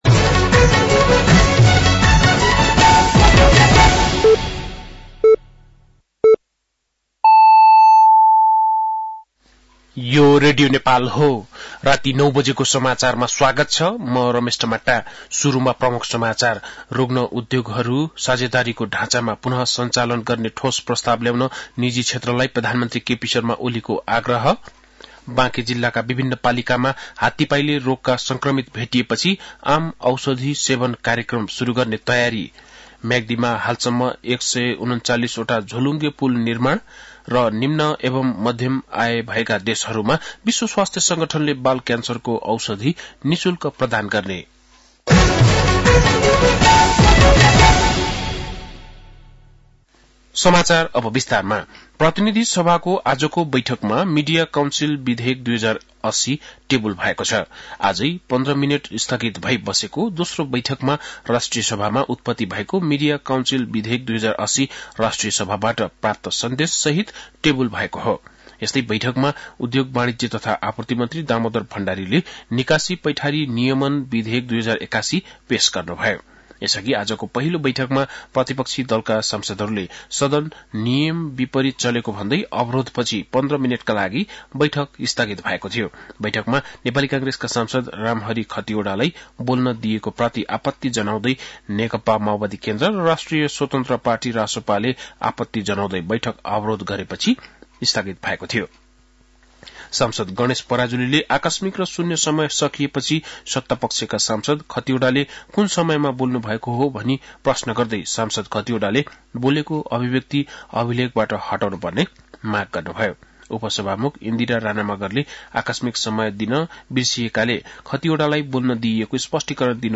बेलुकी ९ बजेको नेपाली समाचार : ३० माघ , २०८१